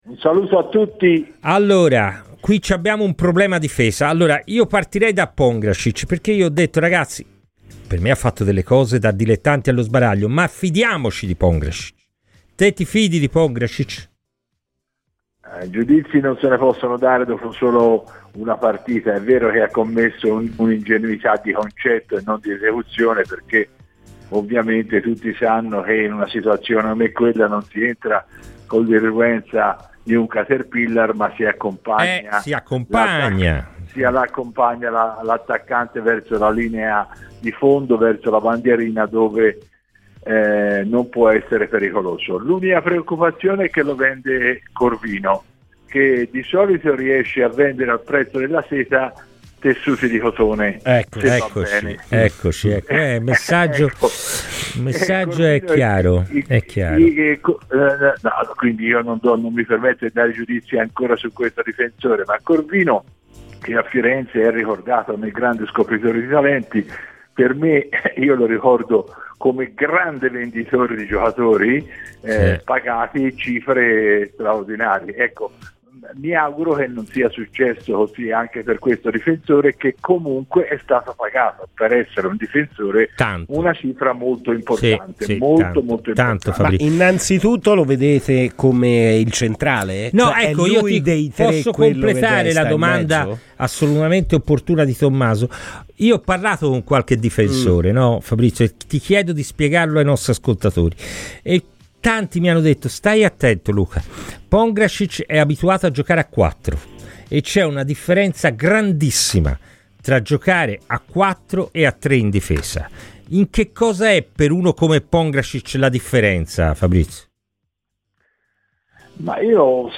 si è espresso così su Radio FirenzeViola nel corso di "Palla al Centro"